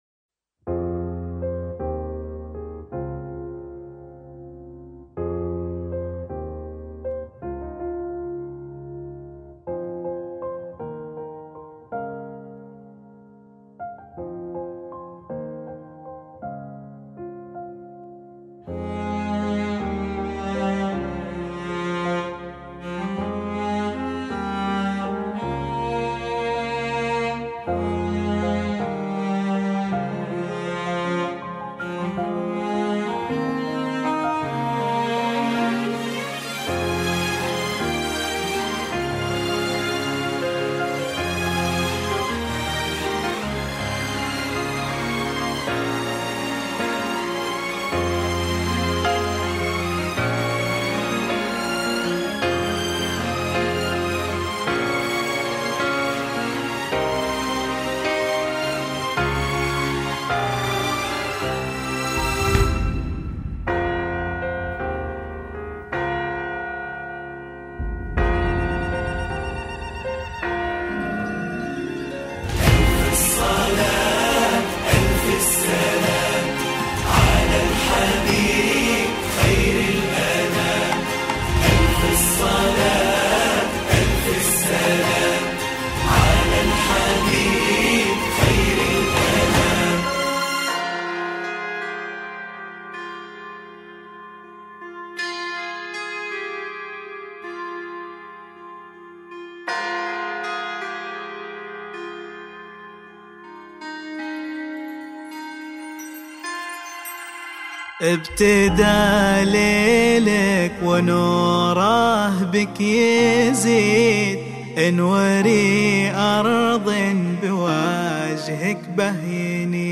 زفات